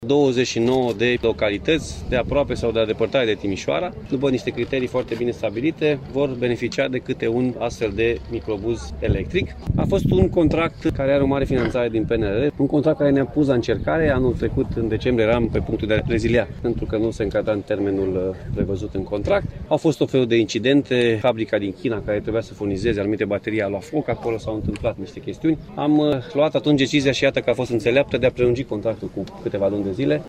Microbuzele au costat 36 de milioane de lei, respectiv 30 de milioane de euro din fonduri europene și șase milioane din bugetul propriu, spune președintele Consilului Județean, Alfred Simonis.
Alfred-Simonis-microbuze.mp3